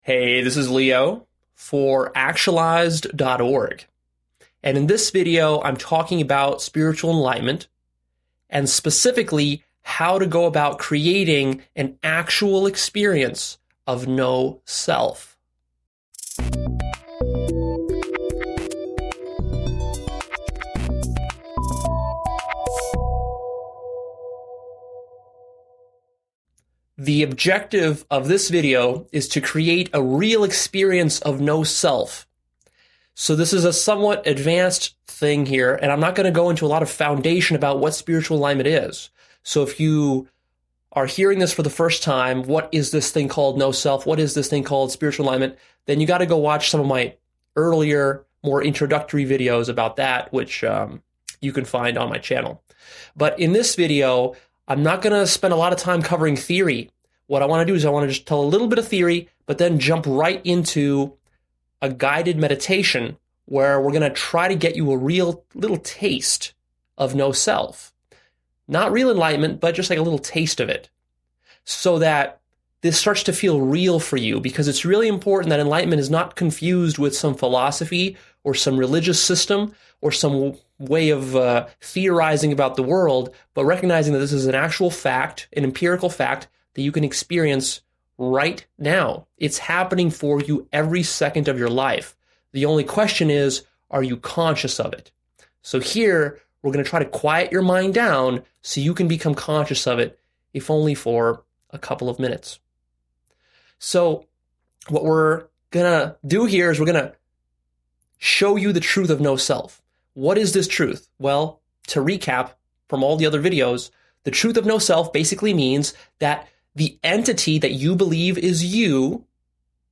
A guided meditation for creating an experience of no-self.